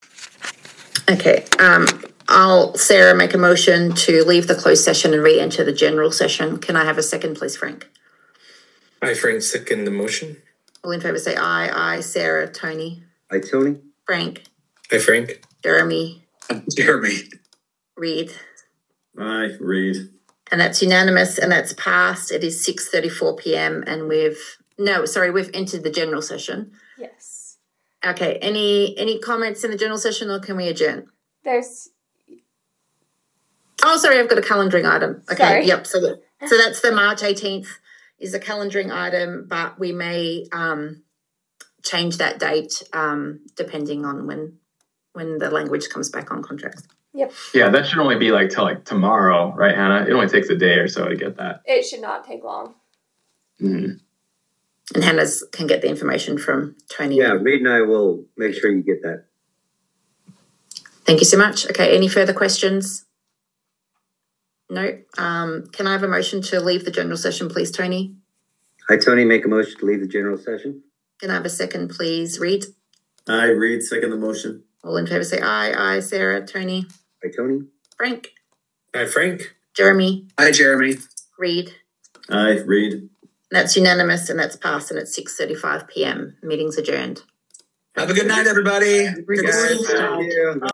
Meeting
One or more board members may participate electronically or telephonically pursuant to UCA 52-4-207.